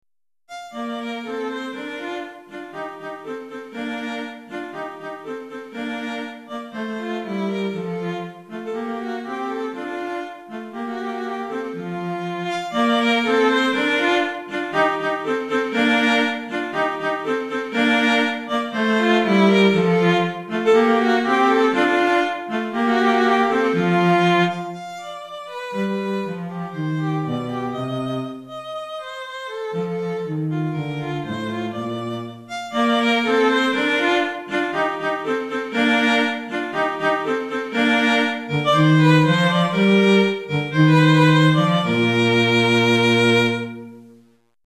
Violon et Violoncelle